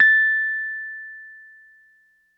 RHODES CL0LL.wav